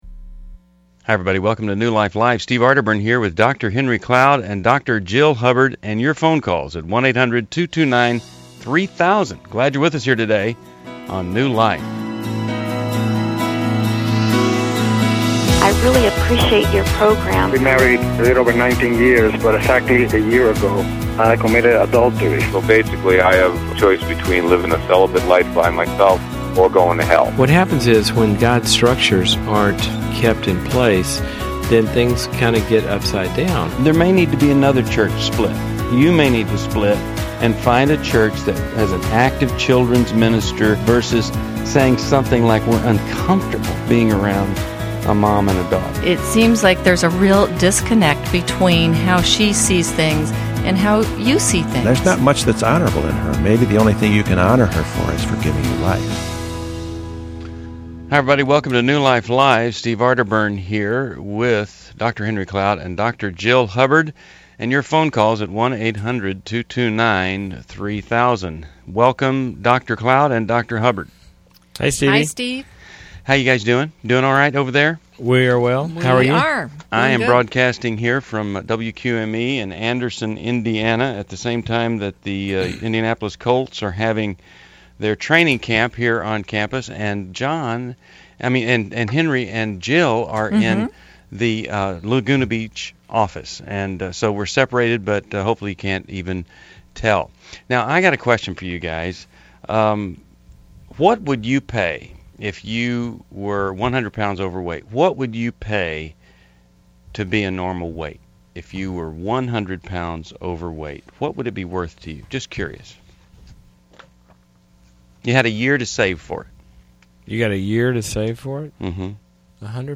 Explore healing and guidance on faith, family, and difficult choices in New Life Live: August 23, 2011, as hosts tackle tough caller questions.